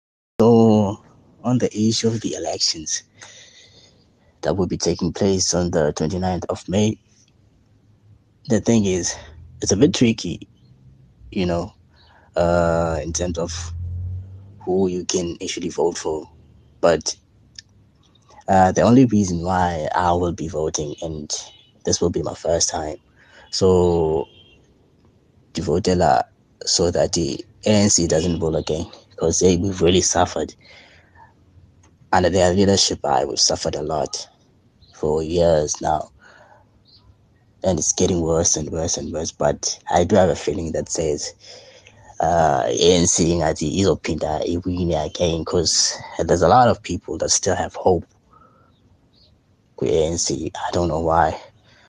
Listen to this voice note to understand why.